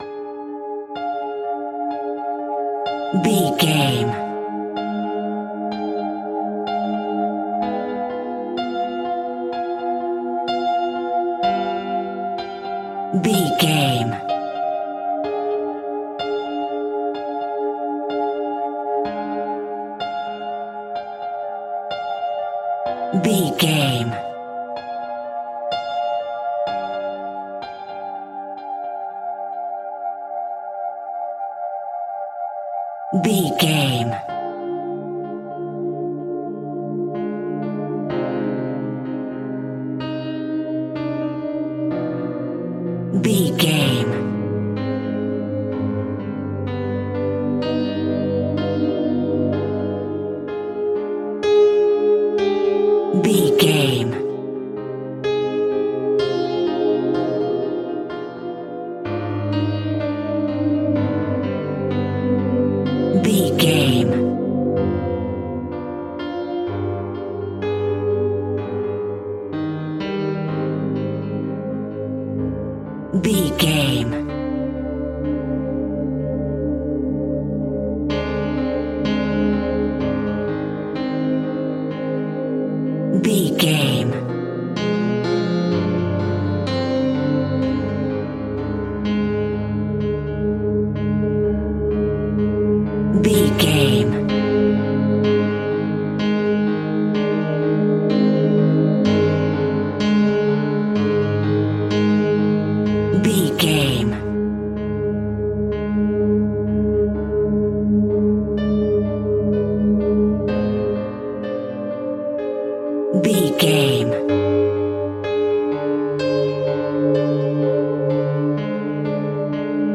Thriller
Aeolian/Minor
Slow
scary
ominous
dark
haunting
eerie
Acoustic Piano